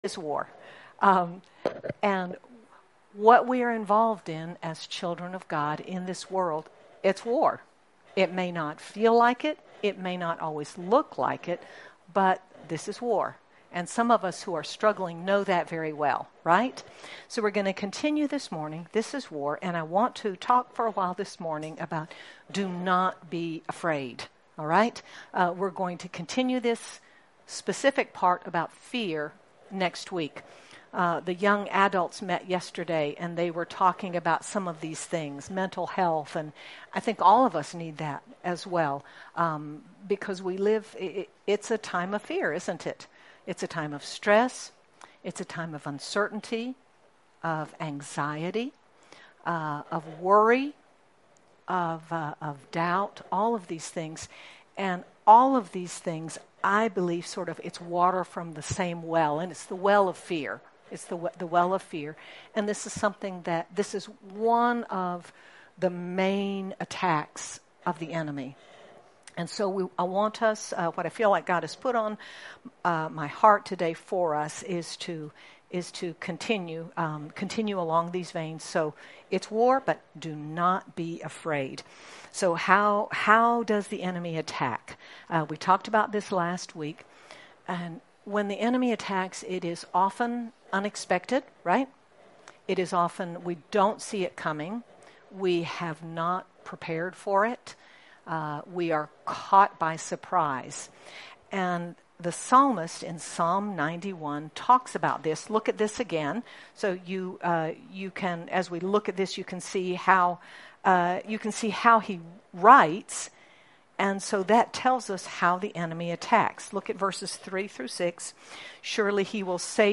Jul 27, 2025 Do Not Be Afraid MP3 SUBSCRIBE on iTunes(Podcast) Notes Discussion Sermons in this Series Recognize that the enemy’s favorite attack against you is fear—but God gives you His presence and His weapons to win over fear every time. Sermon by